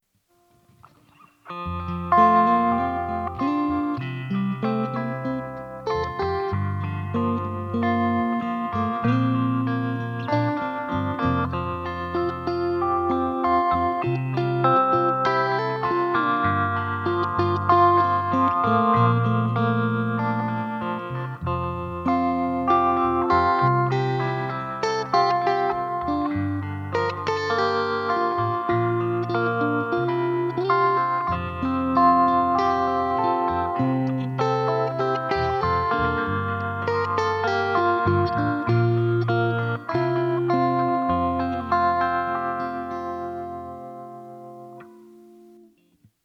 Sehr schöne reine Klänge.